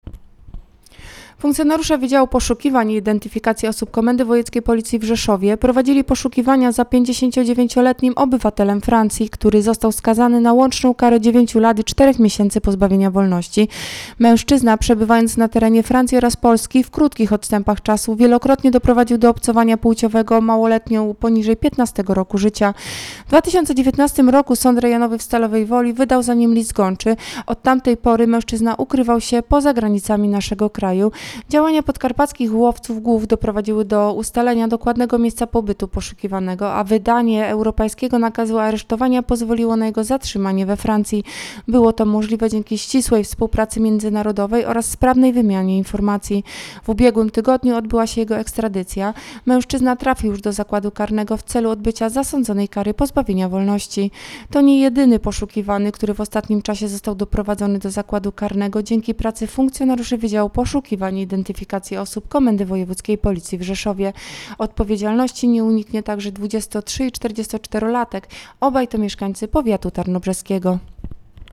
Nagranie audio treści informacji.